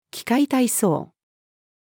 器械体操-female.mp3